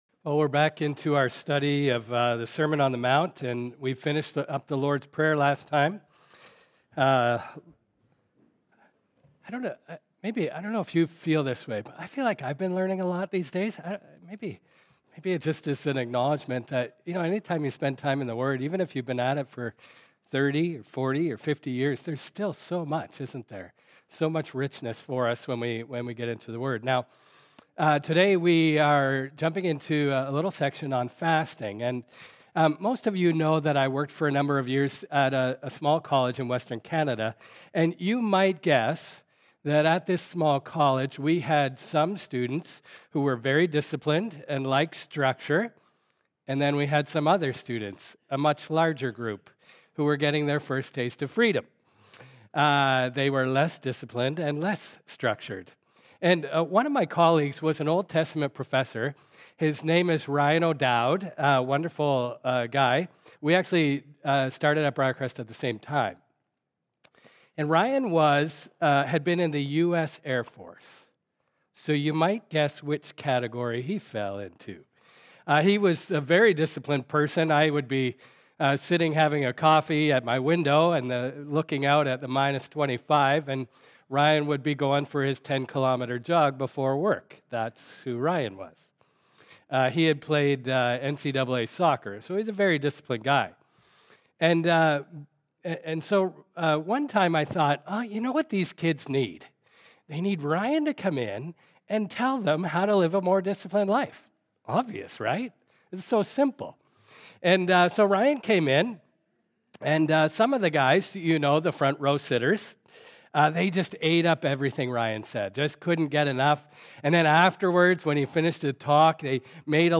Sermons | Bethany Baptist Church